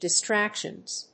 /dɪˈstrækʃʌnz(米国英語)/